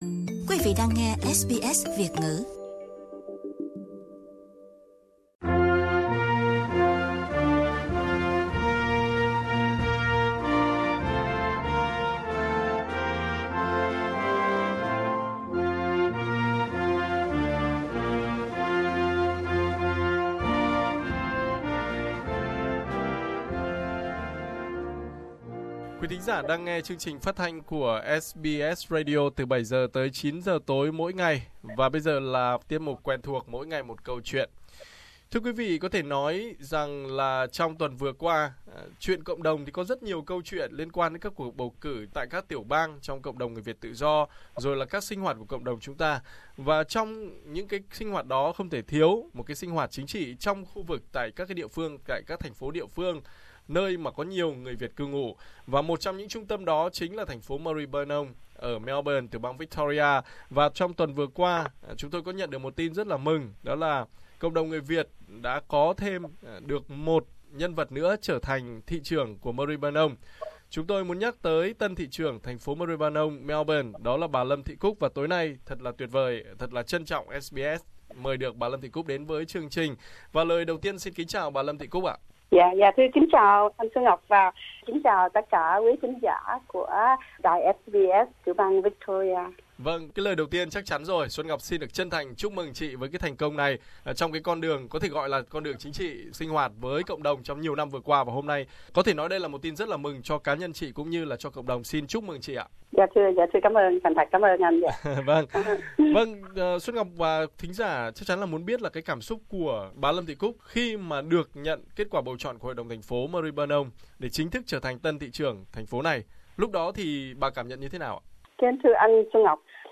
Bà Lâm Thị Cúc, một người Việt tị nạn đến Úc từ 1978 vừa chính thức được bầu làm Thị trưởng Thành phố Maribyrnong, Melbourne. Bà Cúc chia sẻ với SBS Việt Ngữ về chặng đường hoạt động và đóng góp cho cộng đồng tại địa phương và kế hoạch tương lai cho thành phố và đặc biệt là thông điệp cho giới trẻ Việt muốn theo con đường chính trị tại Úc.